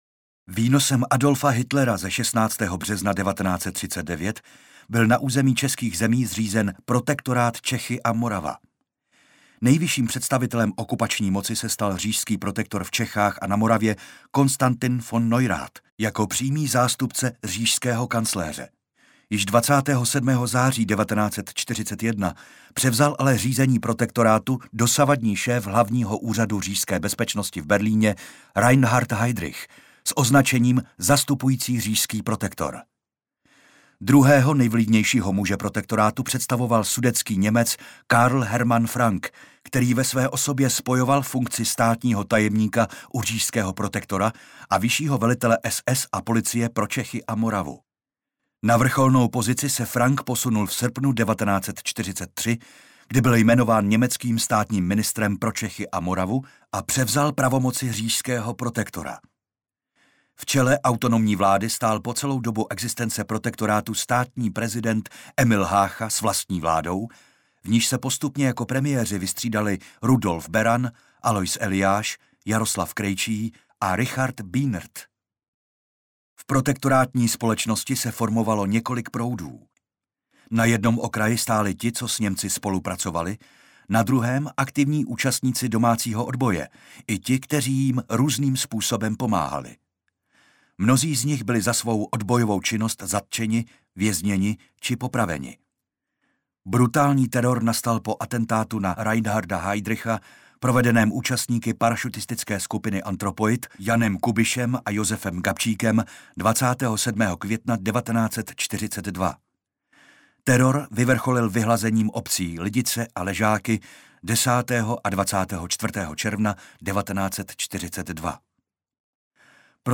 (Adolf Hitler, Sportovní palác 26. září 1938)